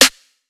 BWB WAV R US SNARE (3).wav